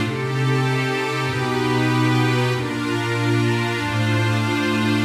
Index of /musicradar/80s-heat-samples/95bpm
AM_80sOrch_95-C.wav